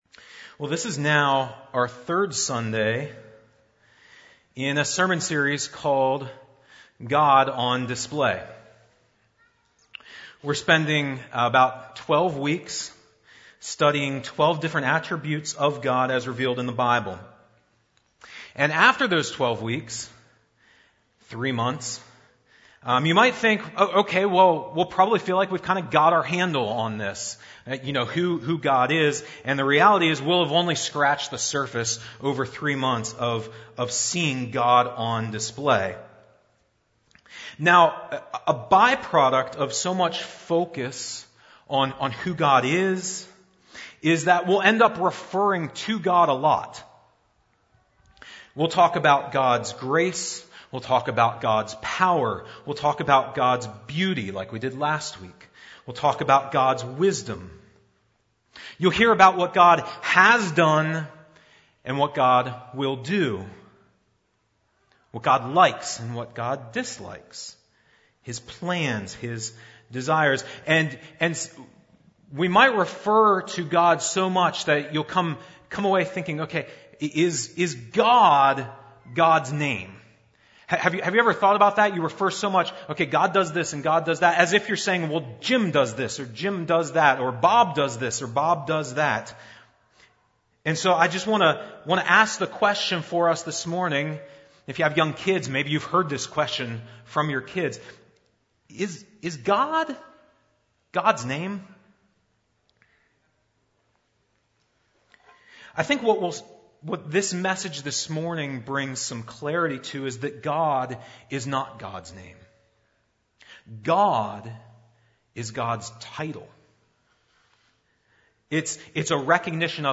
NOTE: There was an audio problem around 15 minutes into the sermon. The audio cuts off and starts again.